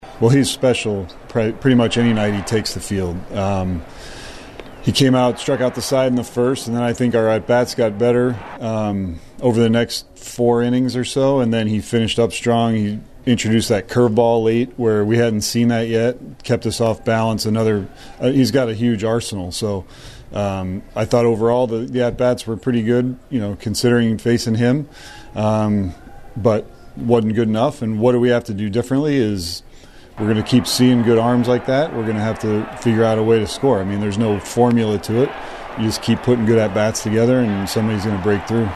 Royals Manager Matt Quatraro said they faced an outstanding pitcher.